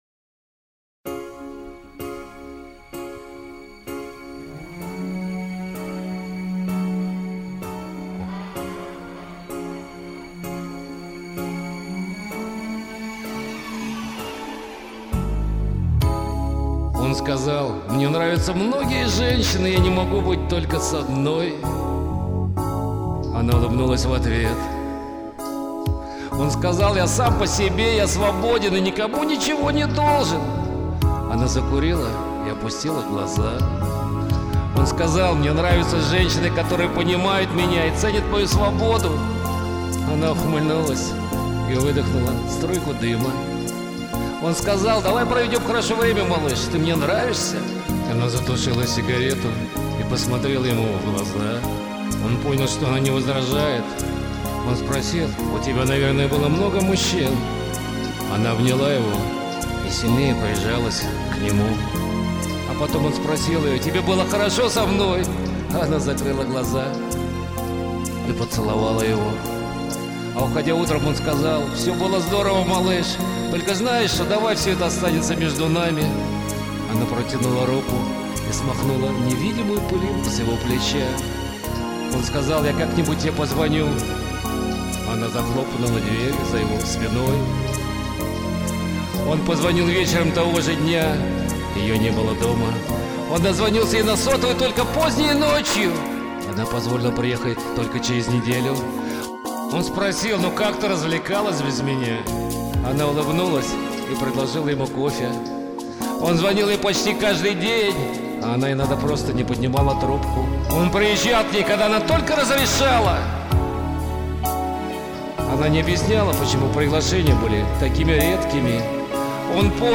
Сейчас это аудио спектакль одного актера, но Я приглашаю всех творческих людей к сотрудничеству!